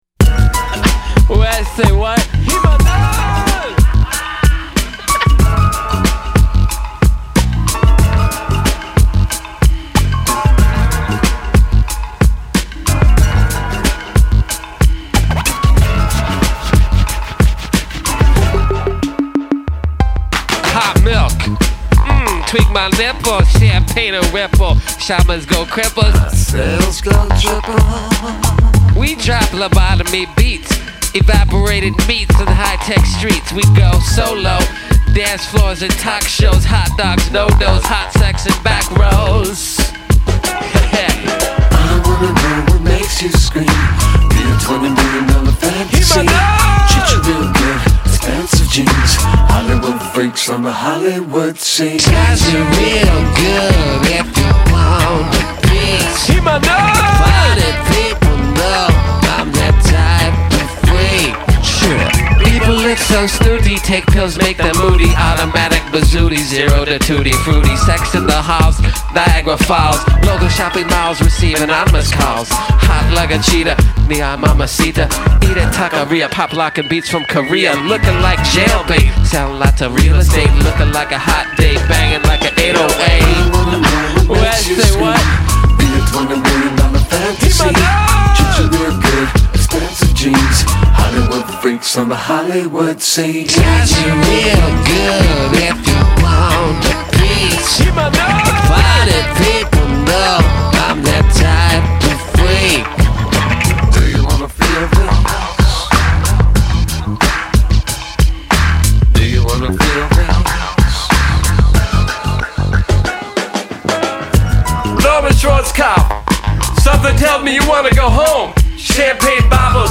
a neon slice of pure electrified funk.
’ an oddball rap song that name-drops No Doz